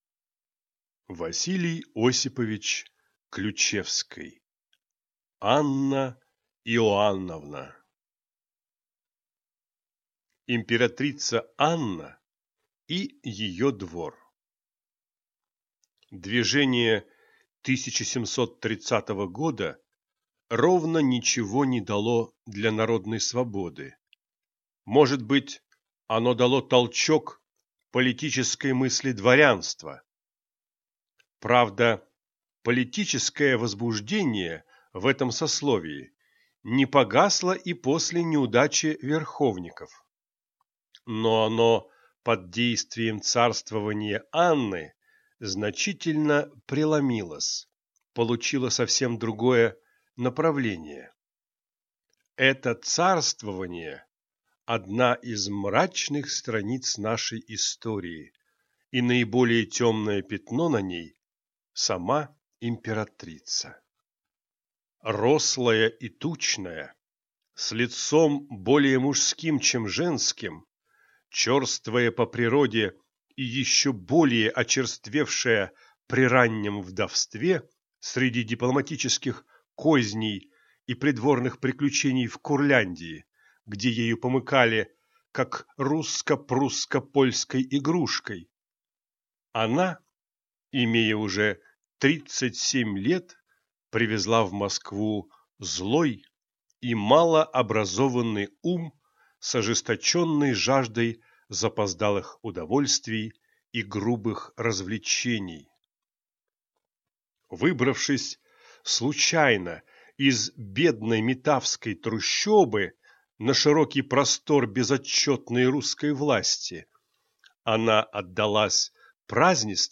Аудиокнига Анна Иоанновна | Библиотека аудиокниг